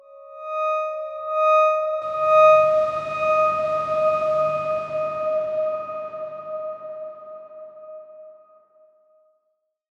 X_Darkswarm-D#5-pp.wav